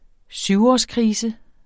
Udtale [ ˈsywɒsˌ- ]